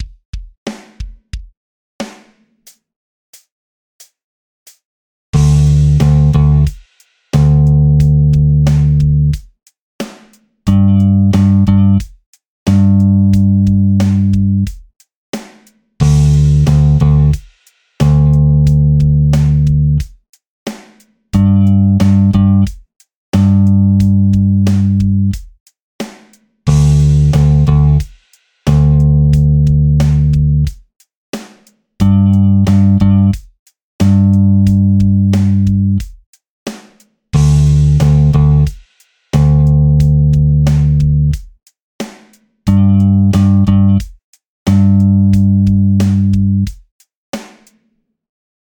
6．開放弦と長い音符／休符を使用したベース練習フレーズ７選！
3．タイ付き長音符が含まれる練習フレーズ
4分音符と2分音符がタイで繋がっている。